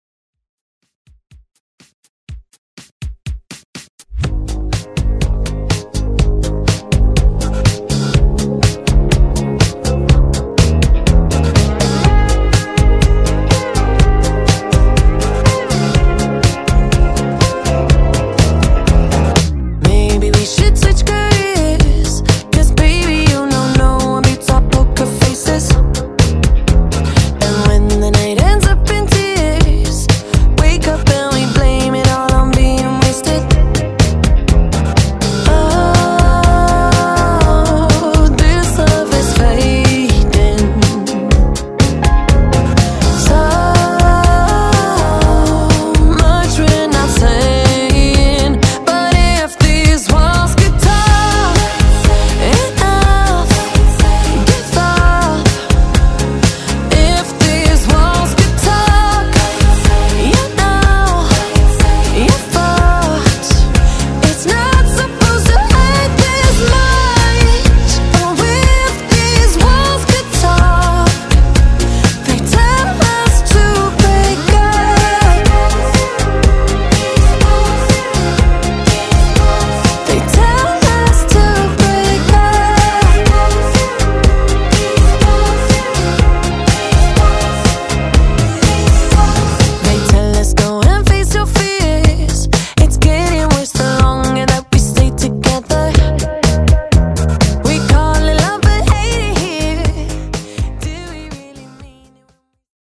Genres: MASHUPS , MOOMBAHTON , TOP40
Clean BPM: 102 Time